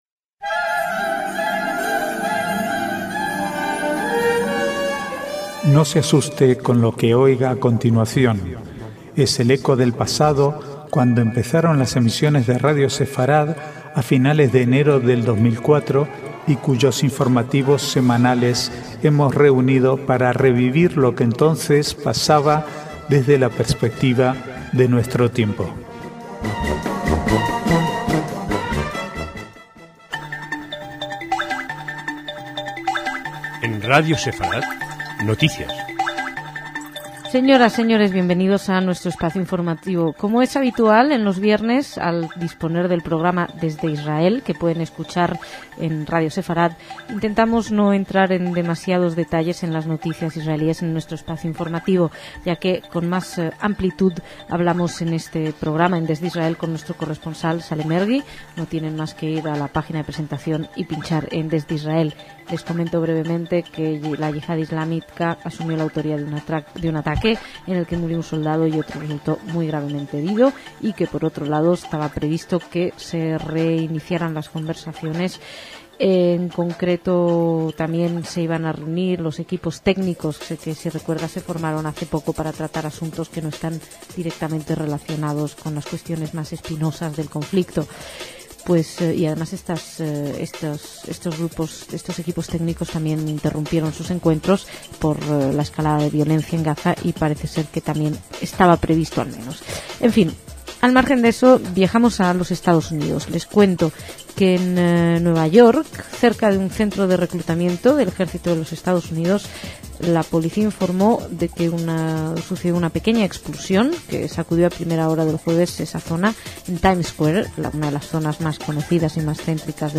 Archivo de noticias del 7 al 12/3/2008